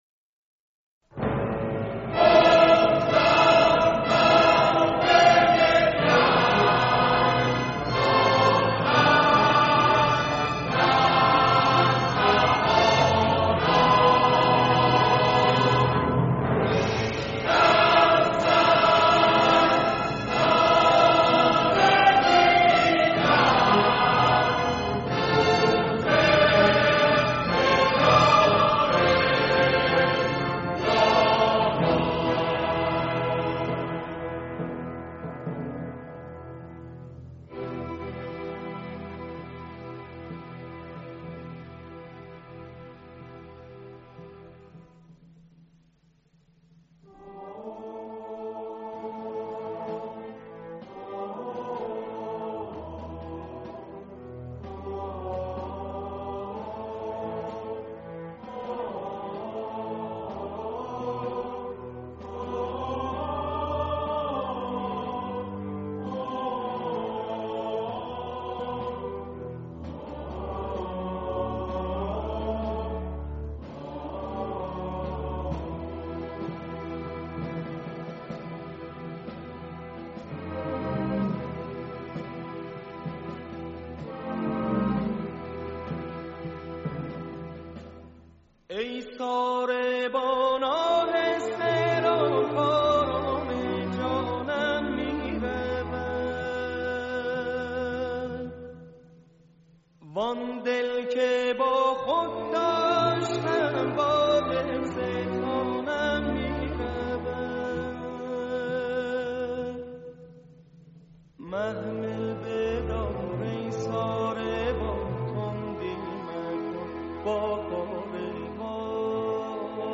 اجرای ارکستر آهنگساز